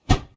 Close Combat Swing Sound 81.wav